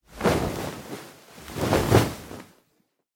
На этой странице собраны звуки, связанные с одеялом: шуршание ткани, легкое движение, уютное тепло.
Звук укрывания человека одеялом (с размахом) (00:03)